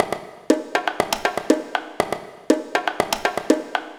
120_bongo_3.wav